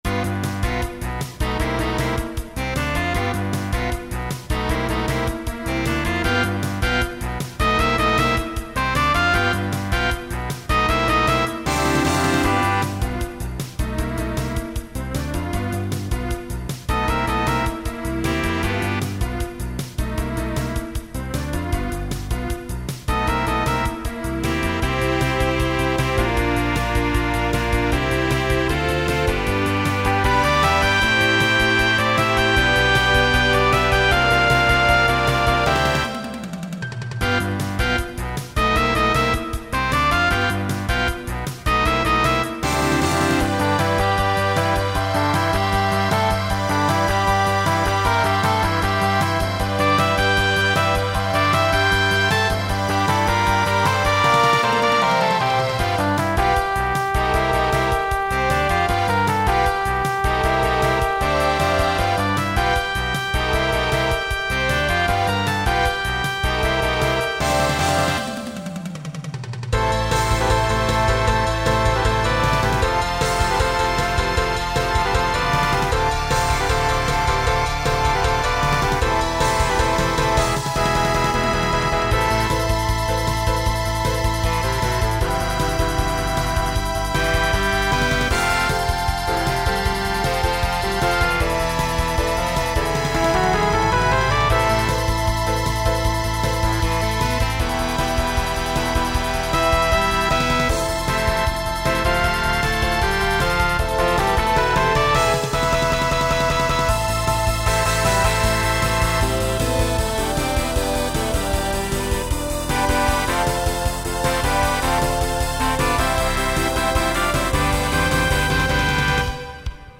TTB/SSA
Voicing Mixed
Genre Rock